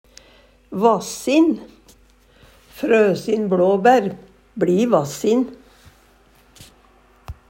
Høyr på uttala Ordklasse: Adjektiv Kategori: Planteriket Attende til søk